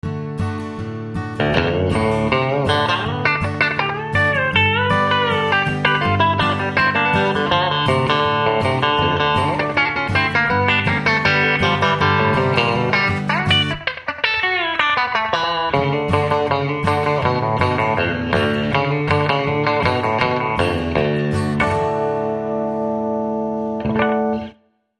Fender Telecaster
Country-Tele
live 1
FenderCountry.mp3